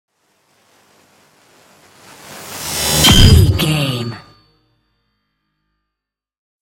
Sci fi whoosh to hit shot
Sound Effects
dark
futuristic
intense
woosh to hit